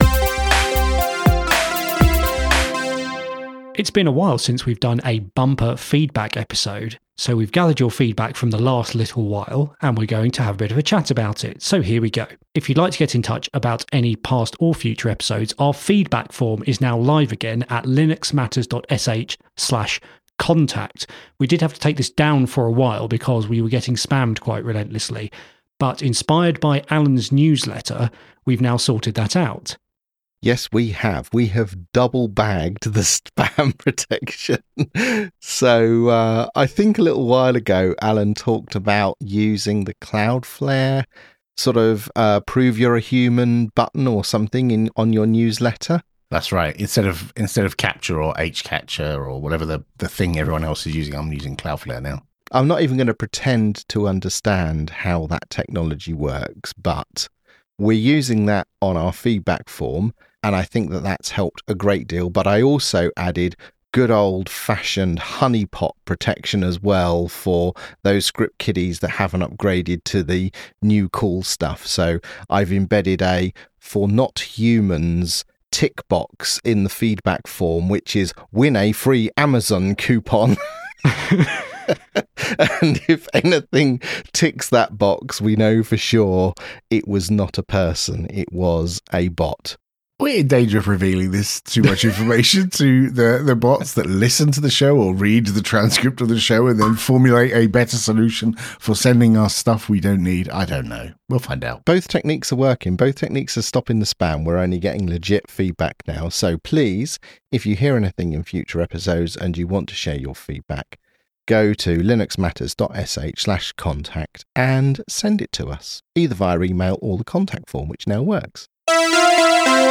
Three experienced Open Source professionals discuss the impact Linux has in their daily lives. Upbeat family-friendly banter, conversation and discussion for Linux enthusiasts and casual observers of all ages.